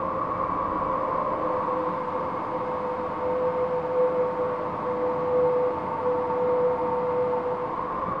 eerie_amb6.WAV